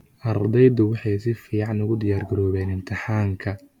Somali-tts / tts_somali_finetuned like 0 Follow Somali-tts 9